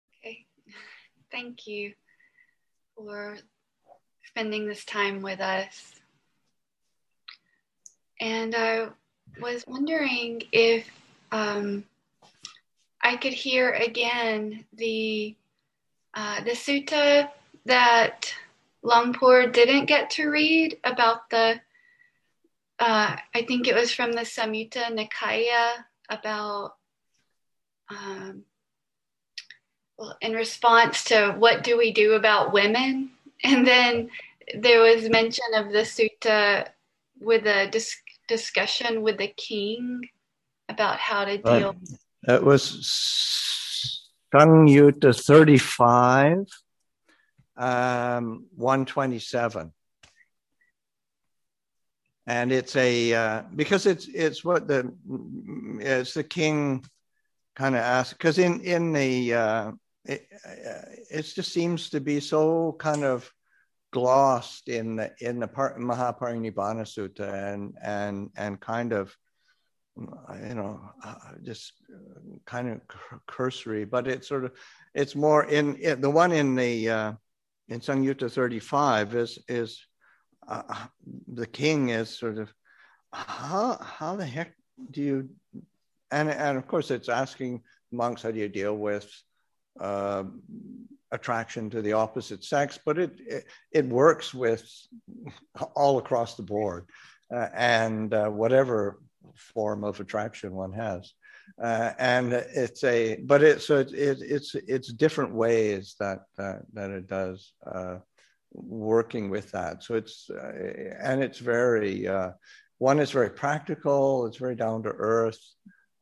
Online from Abhayagiri Buddhist Monastery in Redwood Valley, California